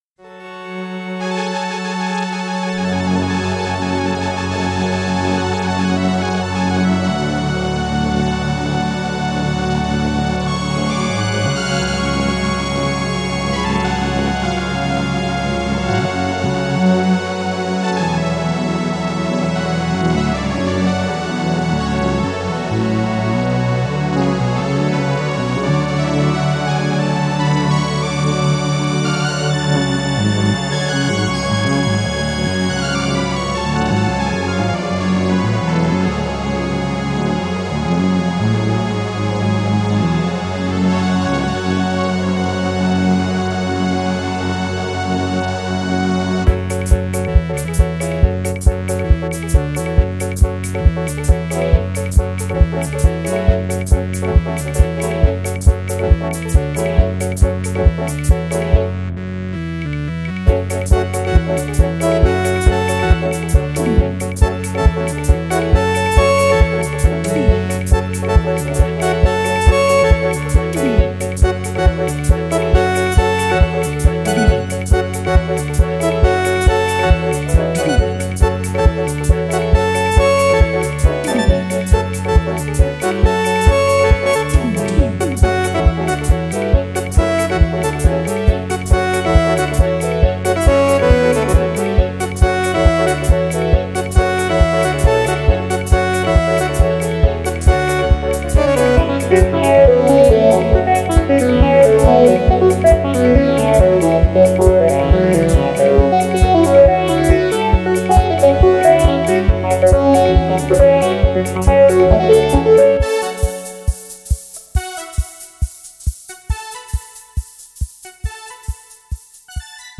Electronix Funk